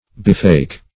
bewake - definition of bewake - synonyms, pronunciation, spelling from Free Dictionary